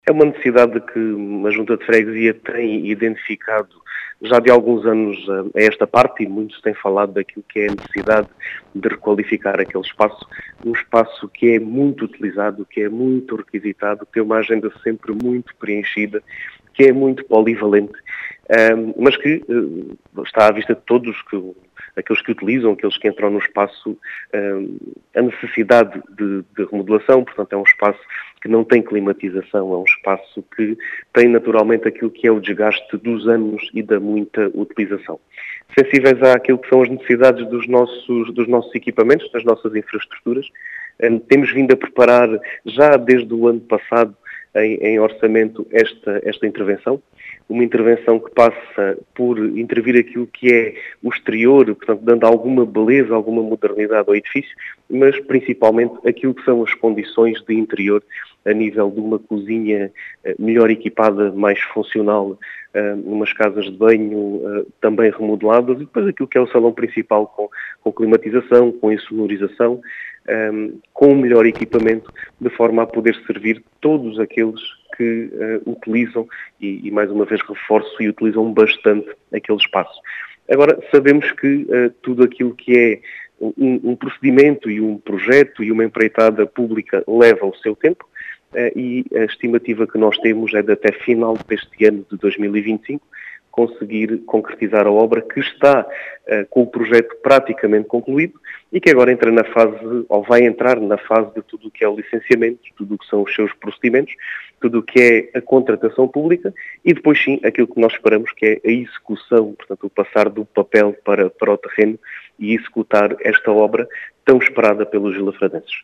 As explicações são do presidente da Junta de Freguesia de Vila de Frades, Diogo Conqueiro, que fala numa “necessidade” identificada pela autarquia, e que deverá estar concretizada até ao final de 2025.